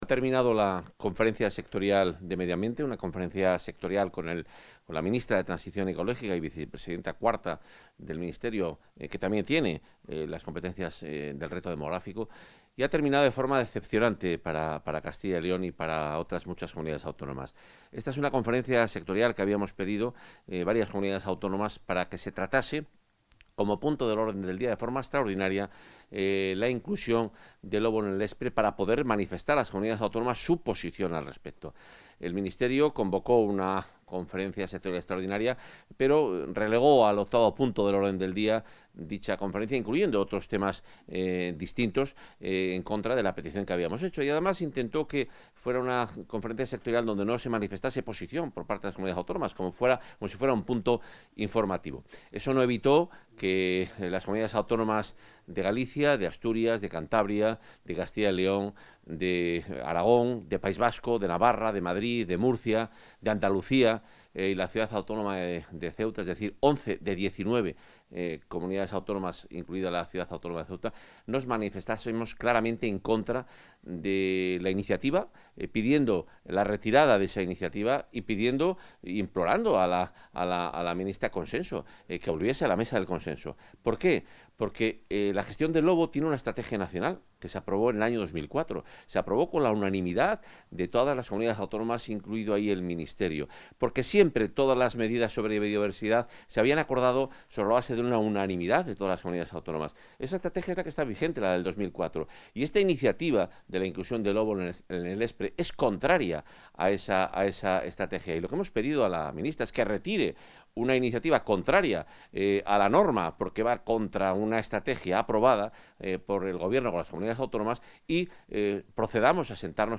Valoración del consejero de Fomento y Meio Ambiente.